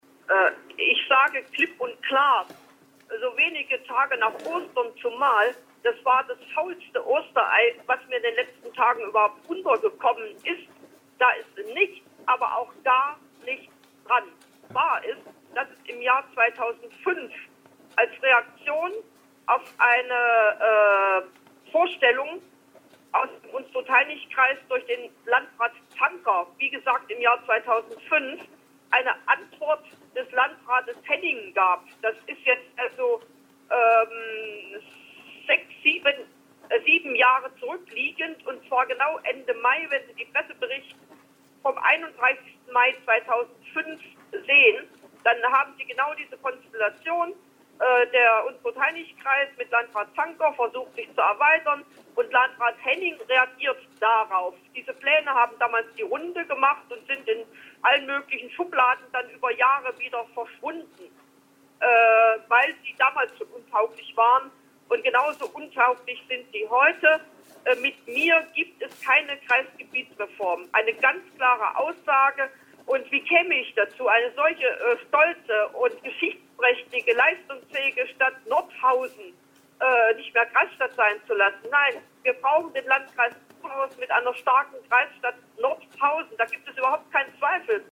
Interview mit Lieberknecht